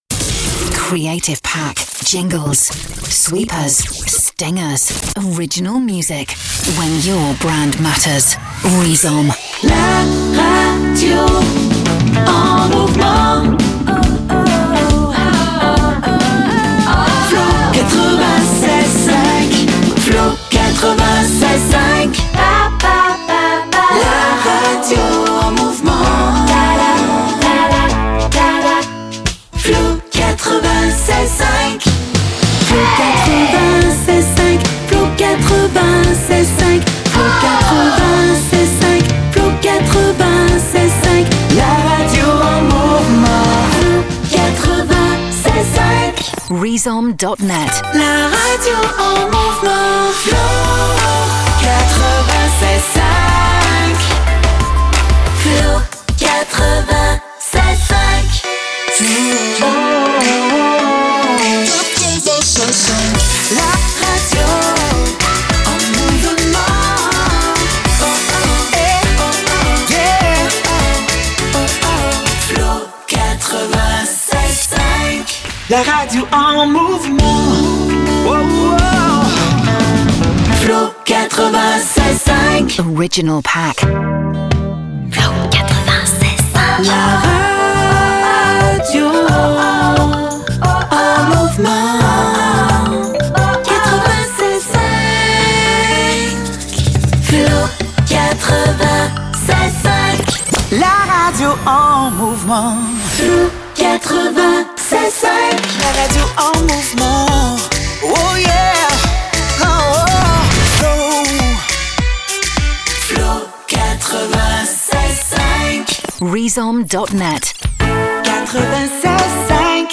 Du "rechanté" / "repiquage" jingles créatifs.